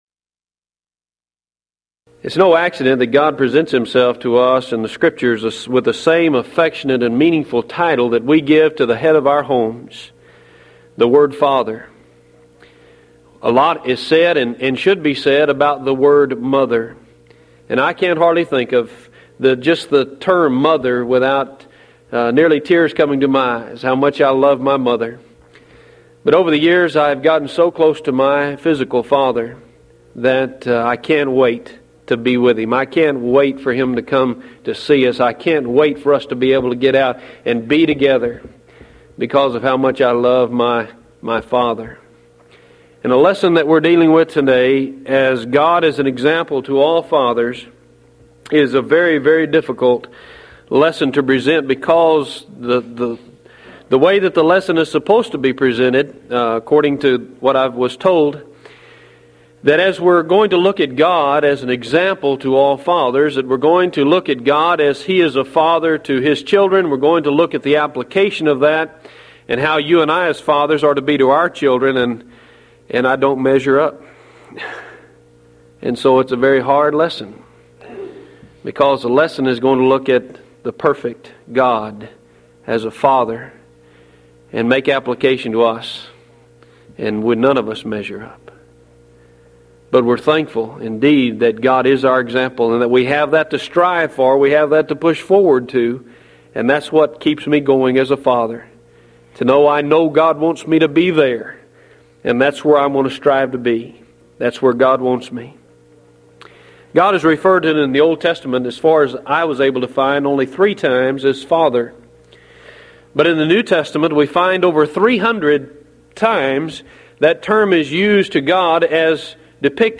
Event: 1993 Mid-West Lectures